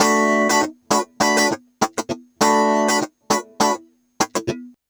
100FUNKY07-L.wav